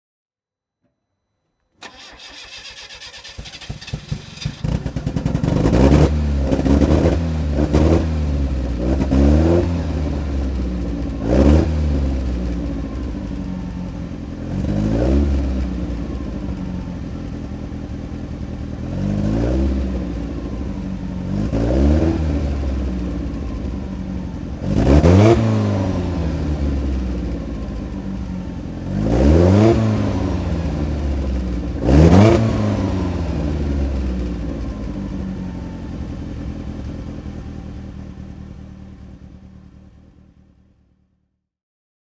To give you an impression of the variety, we have selected ten different engine sounds.
Lotus Esprit S1 (1977) - Starting and idling
Lotus_Esprit_S1_1977.mp3